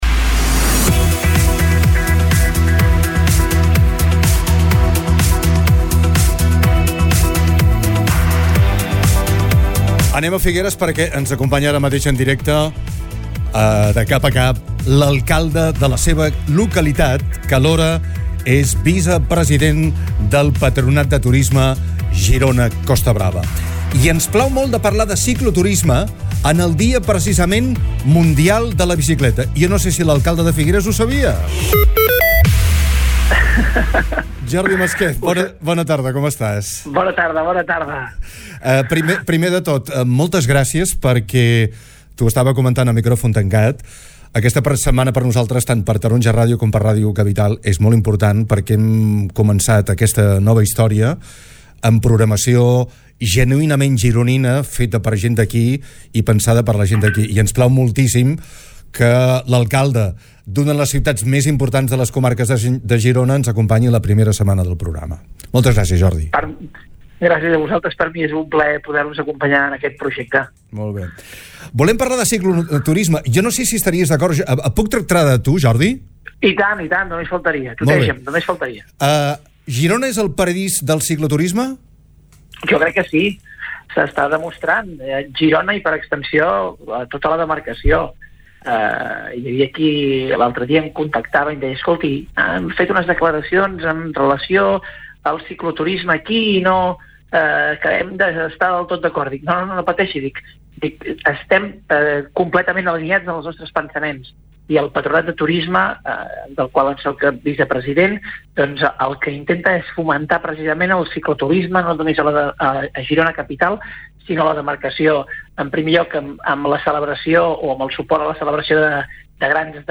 El vicepresident del Patronat i alcalde de Figueres, Jordi Masquef, explica a DE CAP A CAP que Girona és una “destinació top” per al cicloturisme i subratlla que, per evitar morir d’èxit, s’està apostant per “desconcentrar-los” de la ciutat amb propostes a l’interior, la costa i la muntanya.